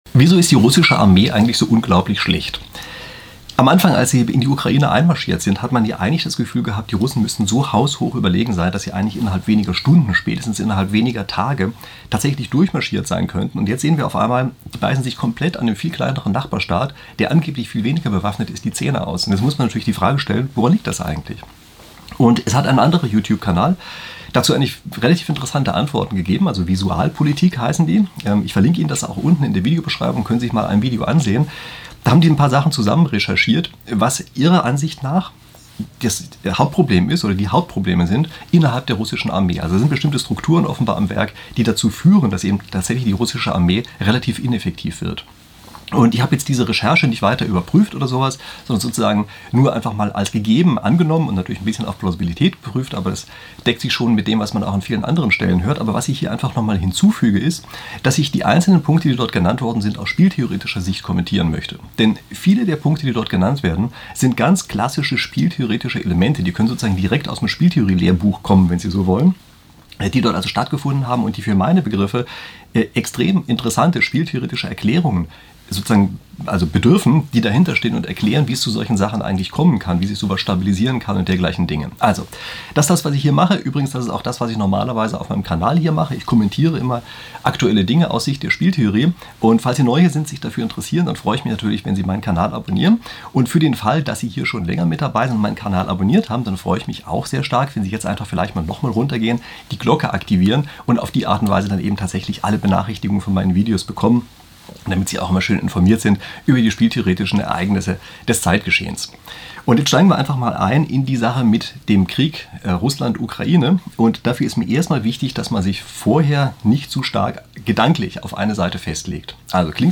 Das Krachen am Ende war das Buch, das mir vom Tisch gefallen ist.